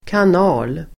Uttal: [kan'a:l]